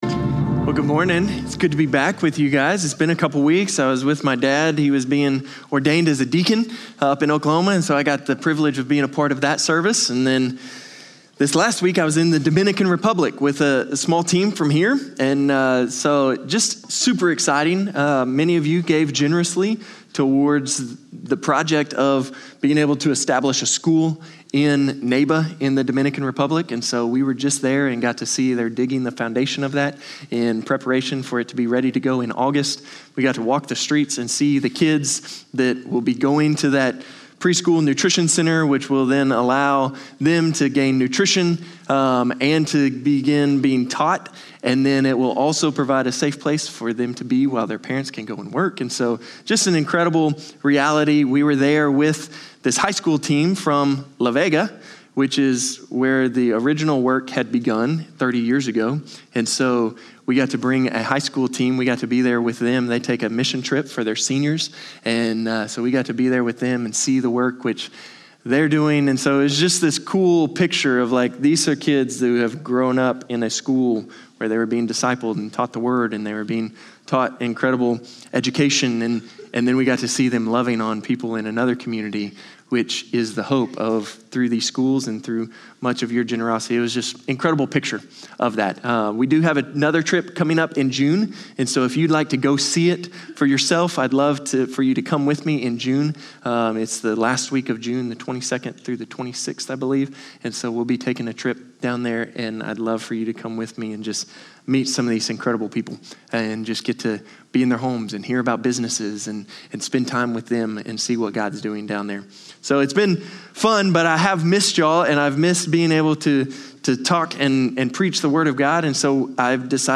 Norris Ferry Sermons Mar. 29, 2026 -- Exodus 7:14-8:19 -- The Plagues Mar 29 2026 | 00:36:06 1x 00:00 / 00:36:06 Subscribe Share Spotify RSS Feed Share Link Embed